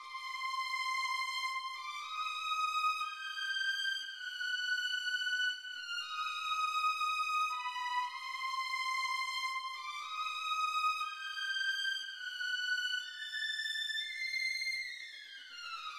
描述：交响乐弦乐
声道立体声